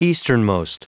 Prononciation du mot easternmost en anglais (fichier audio)
Prononciation du mot : easternmost